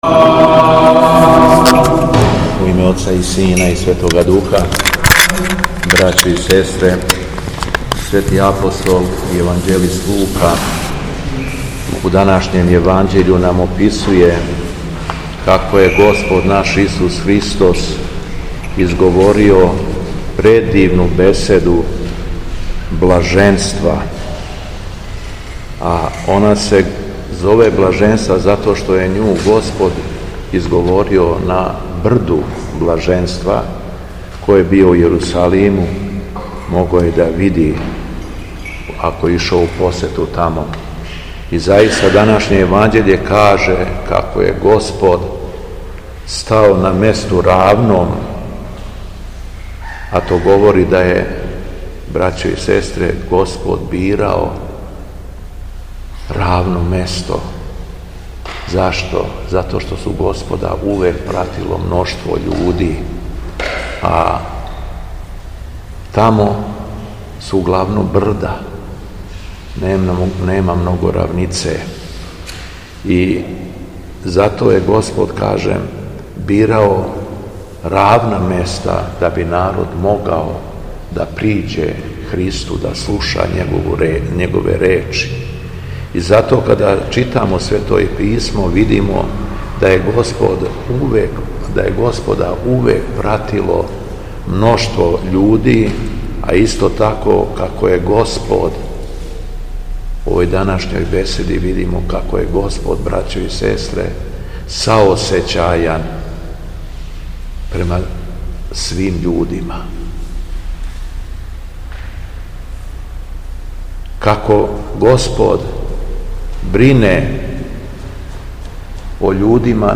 У петак, 18. јула 2025. године, када се наша Света Црква молитвено сећа Преподобног Атанасија Атонског, Његово Високопреосвештенство Митрополит шумадијски г. Јован служио је Свету Архијерејску Литургију у храму Свете Петке у крагујевачком насељу Виногради уз саслужење братства овога светога храма.
Беседа Његовог Високопреосвештенства Митрополита шумадијског г. Јована
После прочитаног јеванђелског зачала Високопреосвећени Митрополит се обратио беседом сабраном народу рекавши: